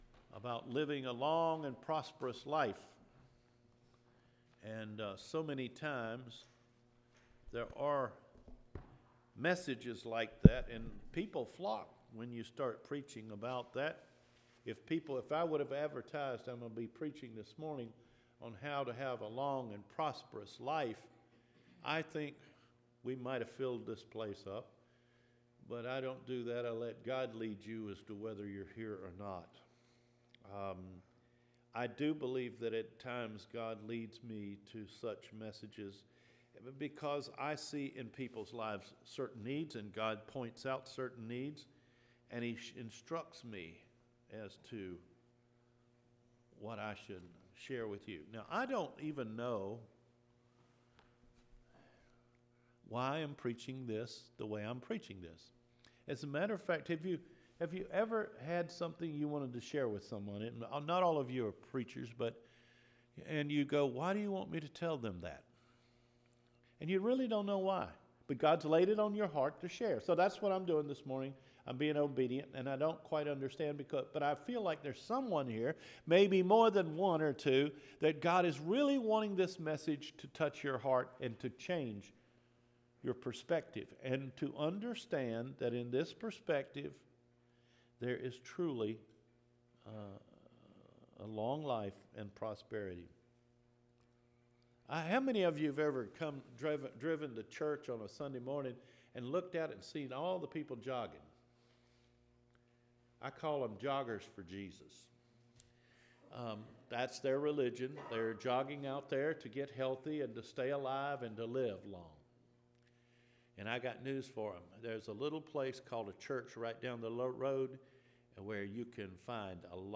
HOW TO LIVE A LONG & PROSPEROUS LIFE – MARCH 8 SERMON